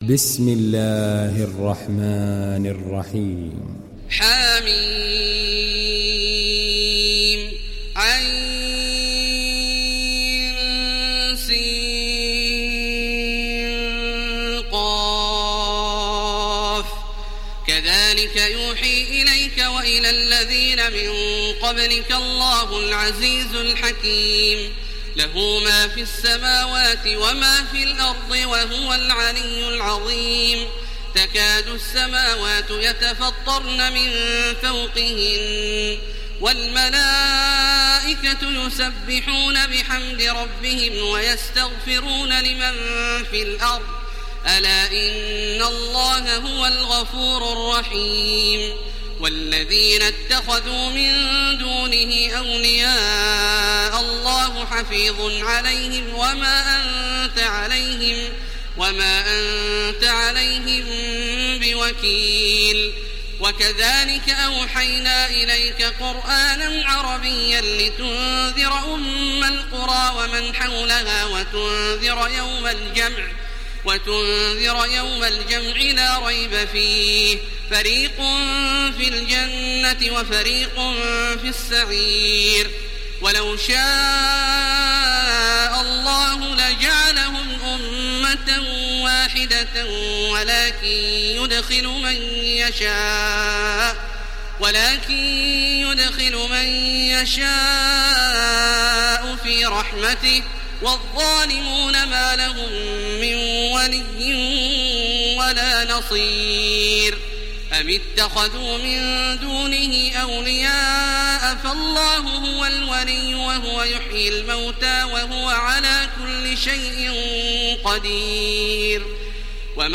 ডাউনলোড সূরা আশ-শূরা Taraweeh Makkah 1430